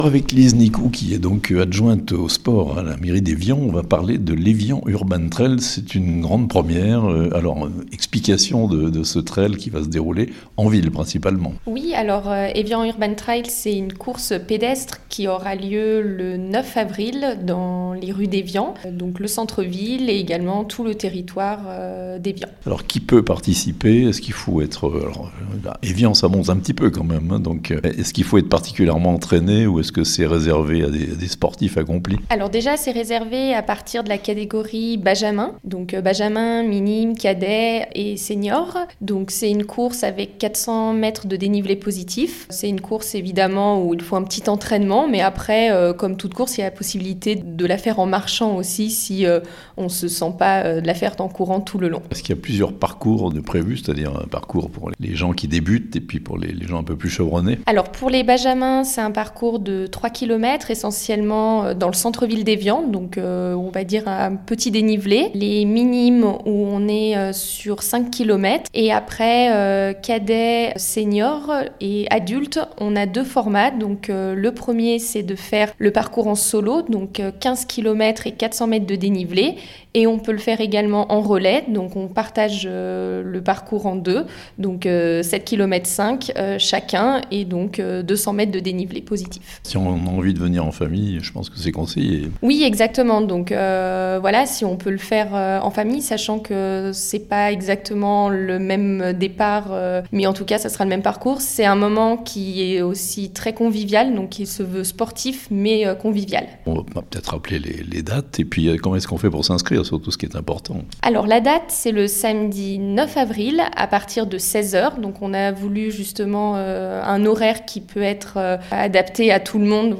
Lancement du 1er Evian Urban Trail (interview)